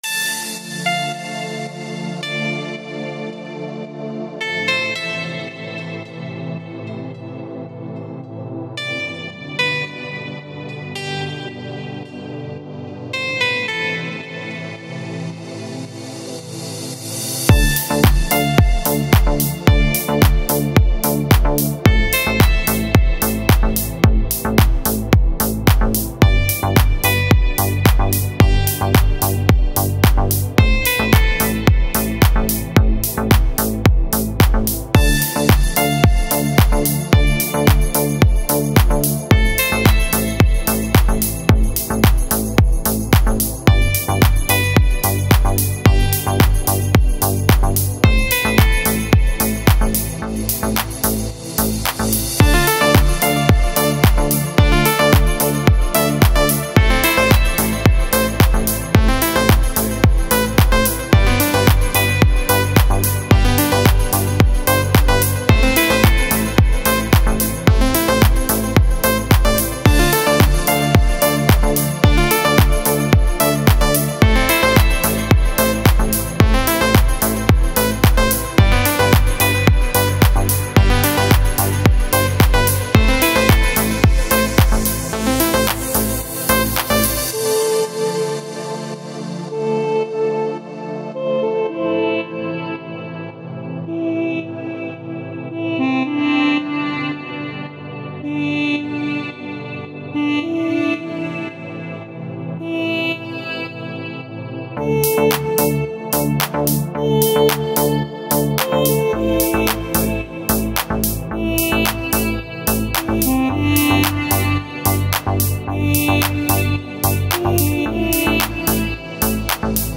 красивая музыка
мелодичная музыка Размер файла